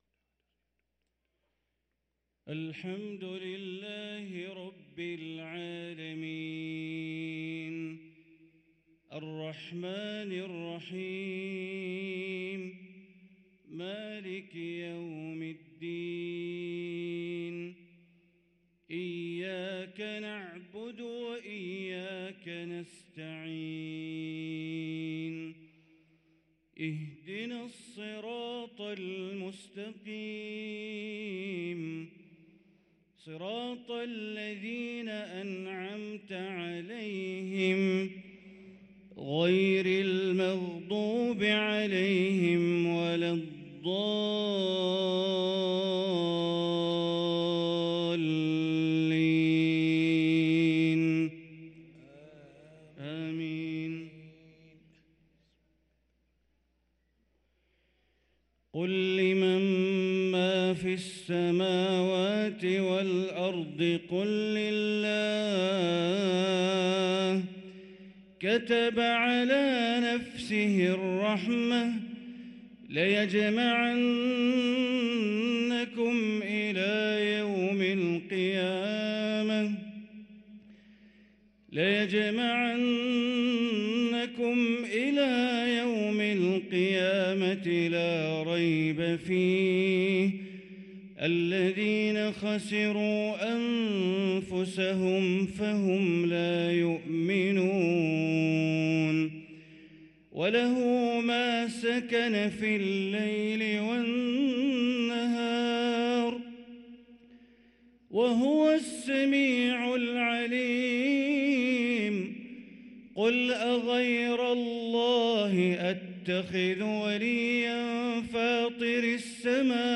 صلاة العشاء للقارئ بندر بليلة 22 جمادي الآخر 1444 هـ